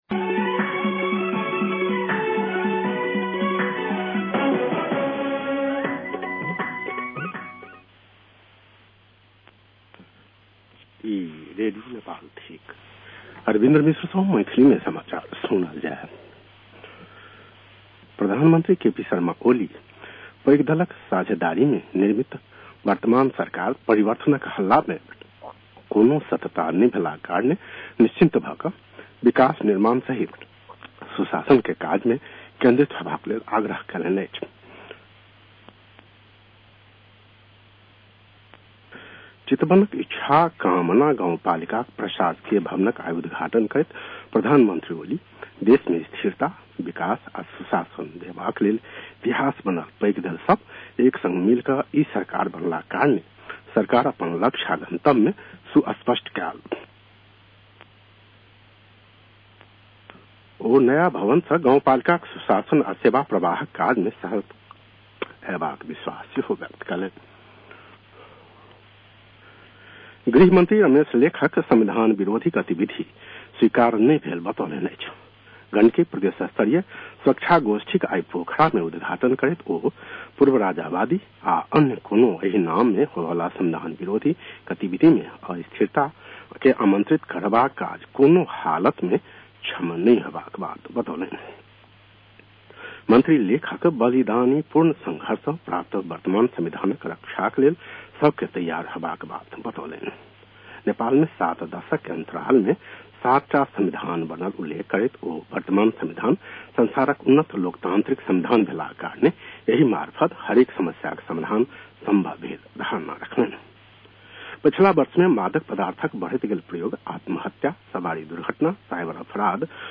मैथिली भाषामा समाचार : ५ वैशाख , २०८२
Maithali-news-1-05.mp3